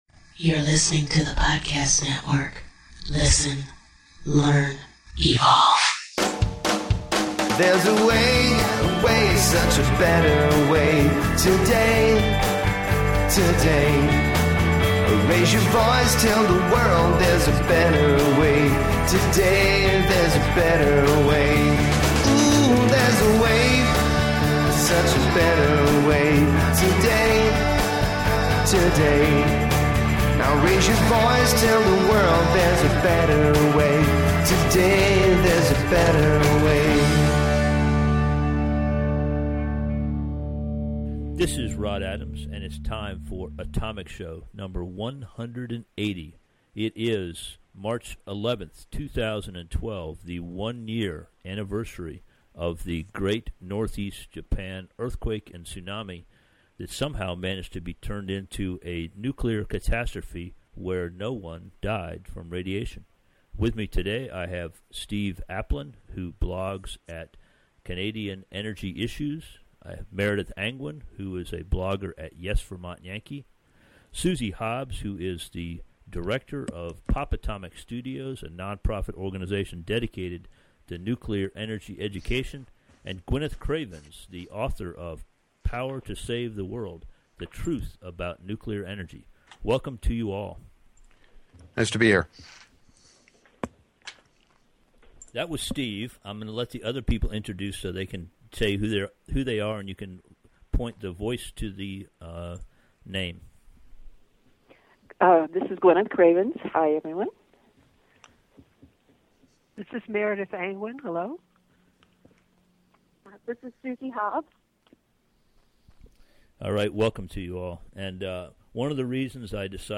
On the anniversary, I gathered a group of people who participated in an effort to share accurate information to counter the purposeful scare mongering provided by professional antinuclear organizations and lapped up by a news media that makes most of its money by selling advertising – often to fossil fuel competitors to nuclear energy.